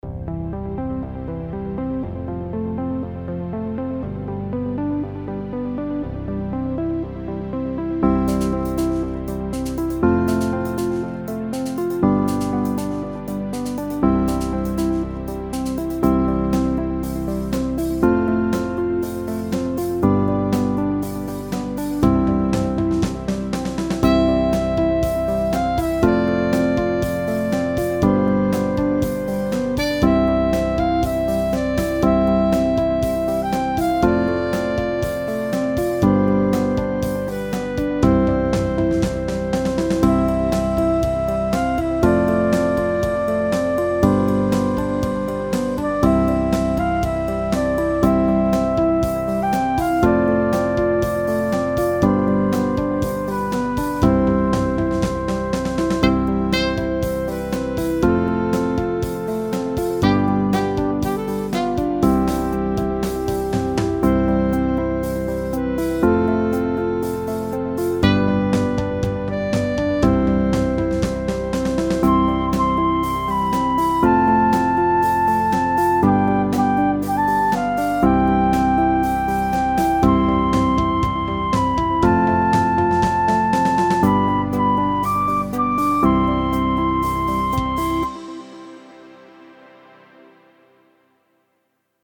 זה מאוד נחמד ורגוע… ביצוע מושלם…